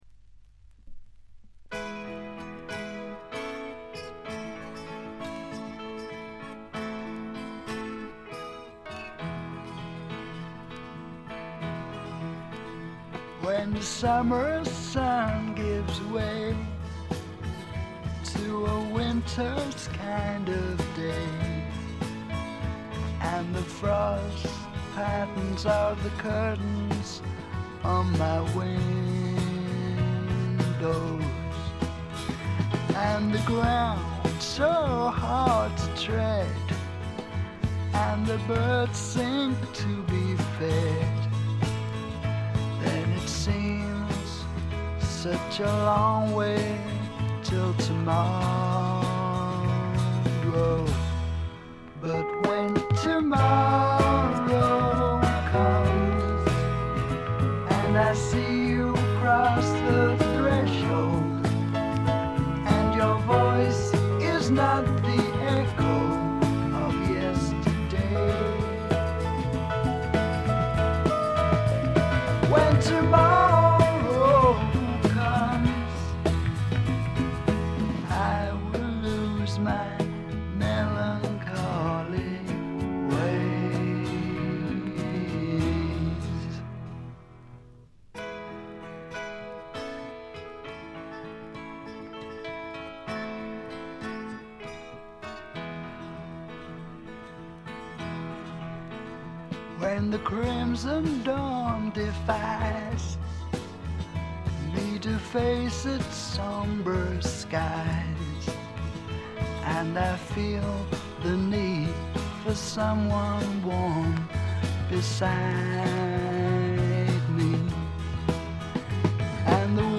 搾り出すような激渋のヴォーカルがスワンプ・サウンドにばっちりはまってたまりません。
試聴曲は現品からの取り込み音源です。